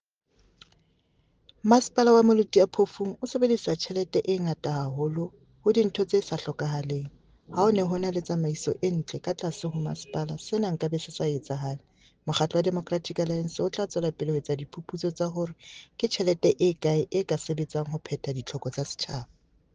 Sesotho soundbite by Cllr Ana Motaung